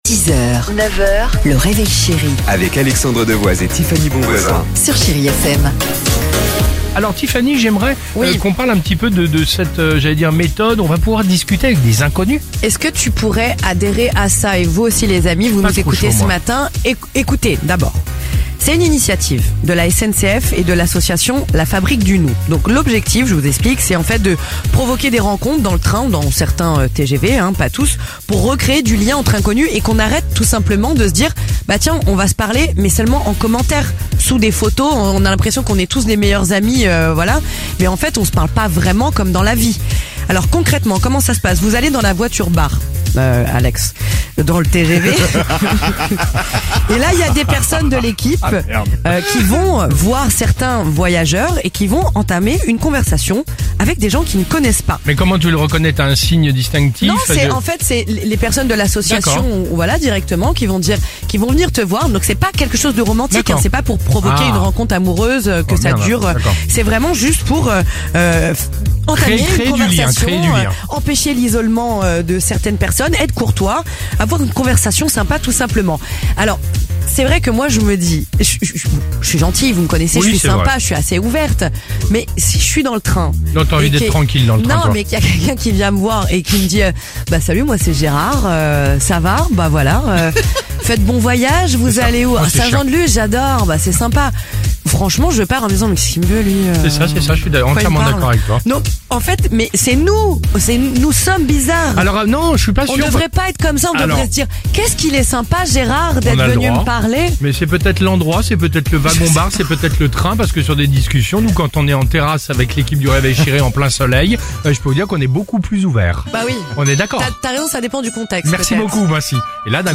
Tous les matins, à 6h10 et 6h50 sur Chérie FM